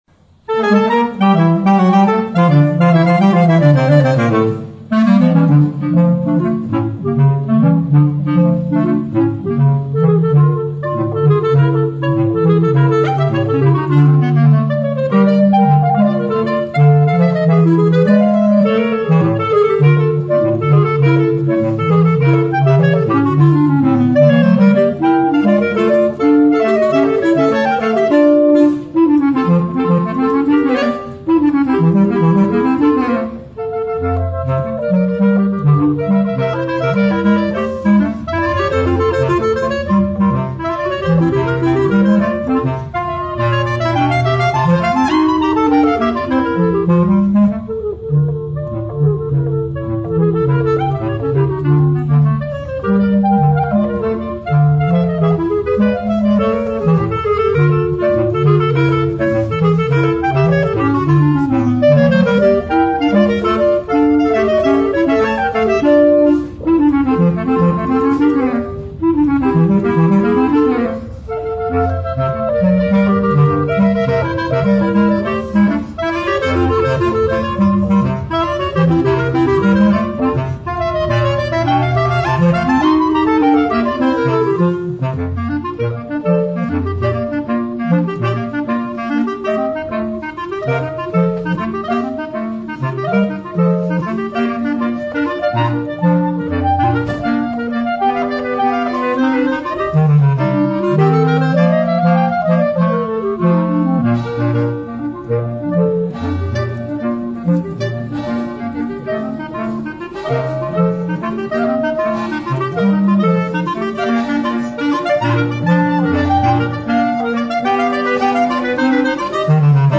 PER 2 CLARINETTI E CLARINETTO BASSO